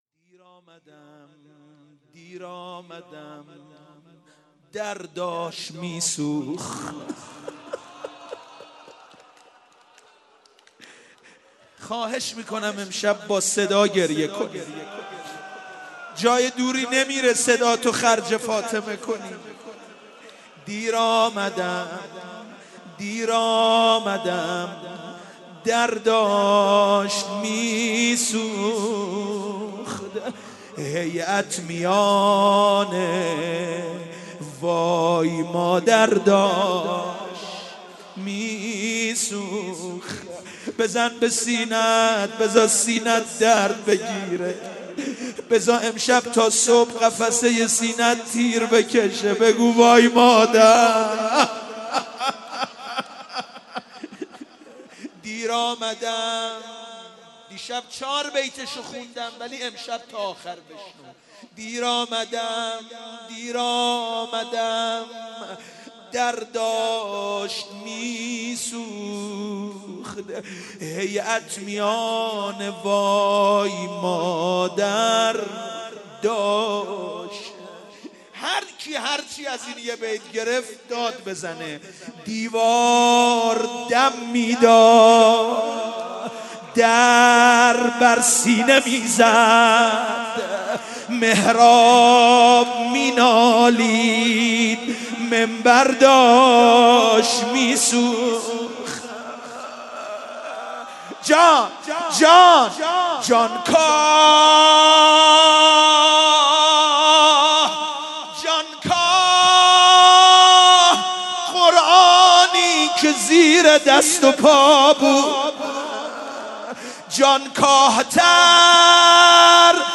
شب دوم_هیئت رزمندگان اسلام قم_روضه_دیر آمدم در داشت می سوخت